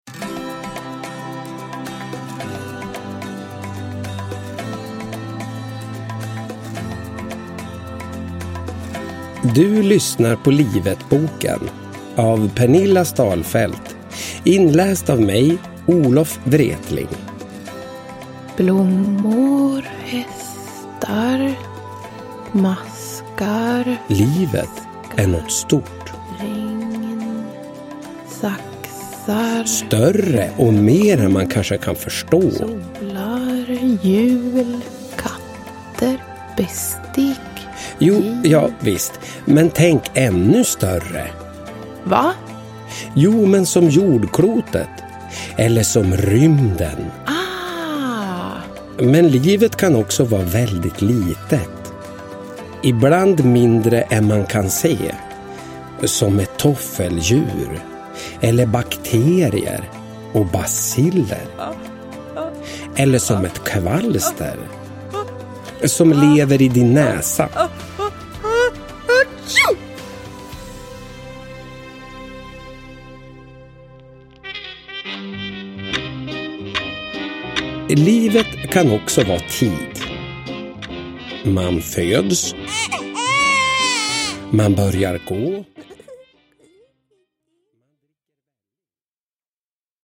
Livetboken – Ljudbok – Laddas ner
Uppläsare: Olof Wretling